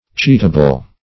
Cheatable \Cheat"a*ble\